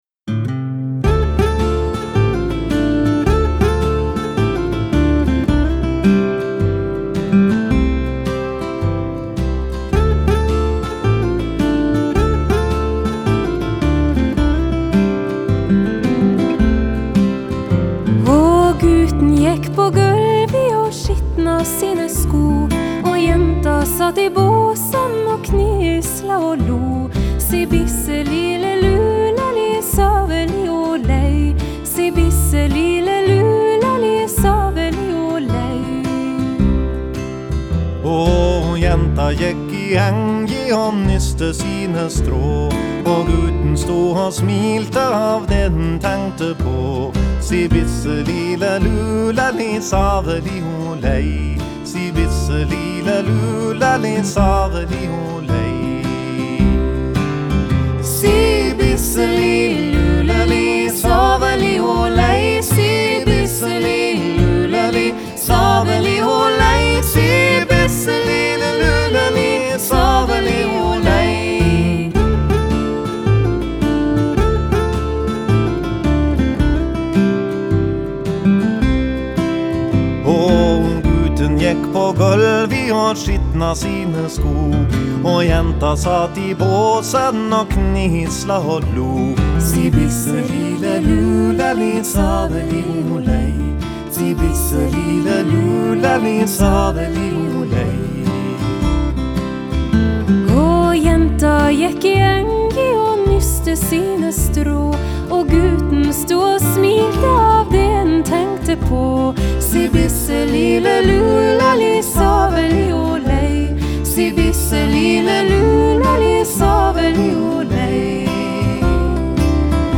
Genre: Folk-Rock, Folk-Pop, Folk
vocals, Guitar, banjo, harmonica, percussion, accordion